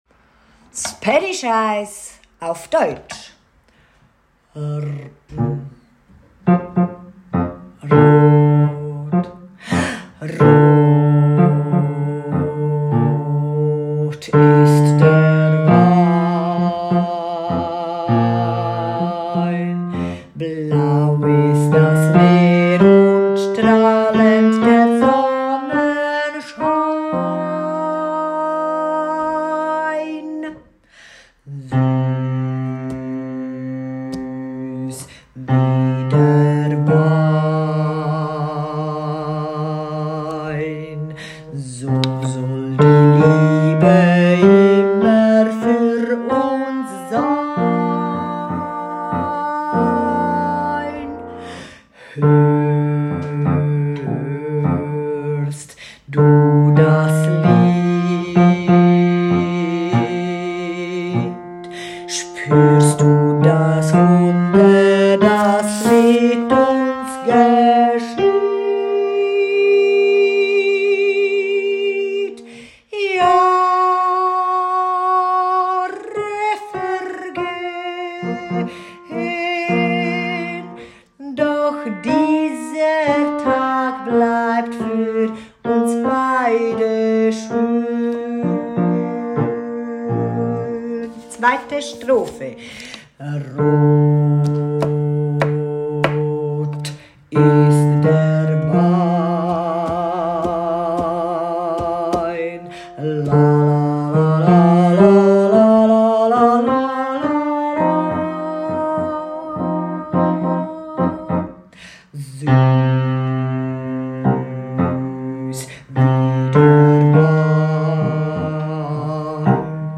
2. Tenor
20_Rot ist der Wein (gilt für Tenor 1 und 2) ==> Text